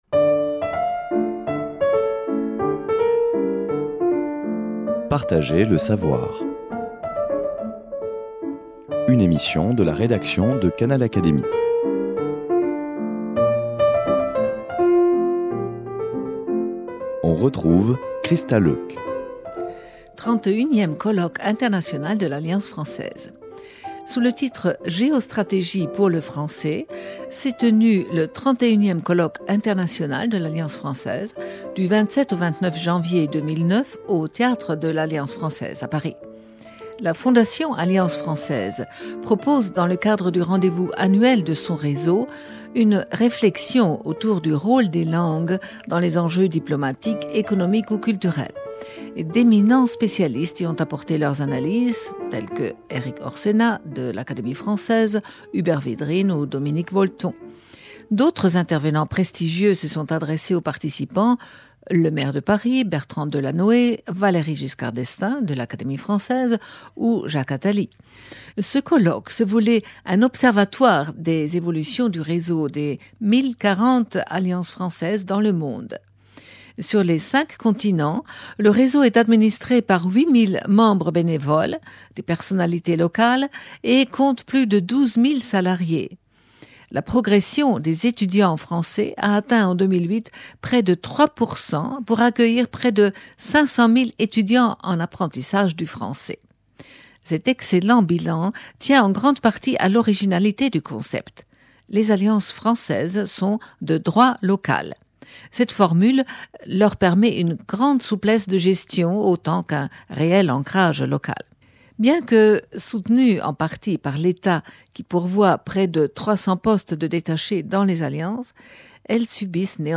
Canal Académie vous présente dans cette émission l'essentiel de la première journée de ce colloque international de l’Alliance française qui s’est tenu à Paris au Théâtre de l’Alliance Française du 27 au 29 janvier 2009, devant quelques 500 participants, présidents, directeurs et délégués des Alliances françaises venus du monde entier.